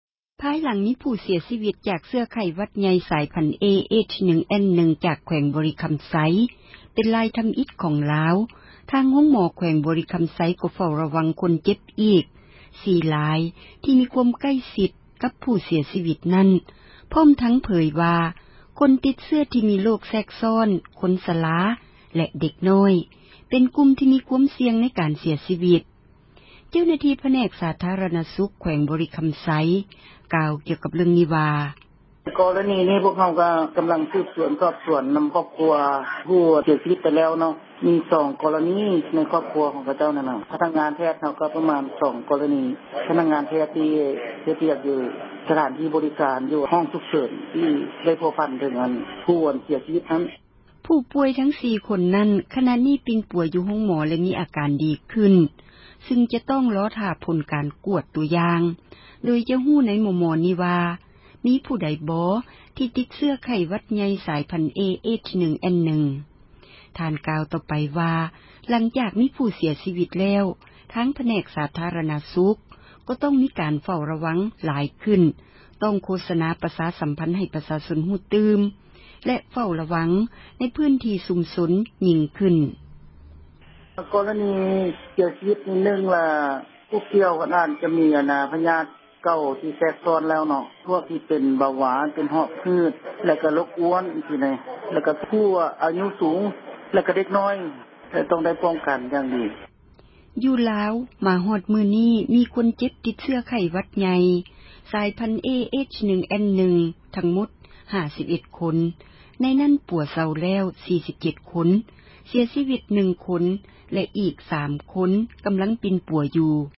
ເຈົ້າໜ້າທີ່ຜແນກ ສາທາຣະນະສຸກ ແຂວງບໍຣິຄໍາໄຊ ກ່າວກ່ຽວກັບເລື້ອງນີ້ວ່າ: